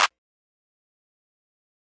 Southside Clap.wav